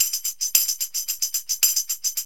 TAMB LP 110.wav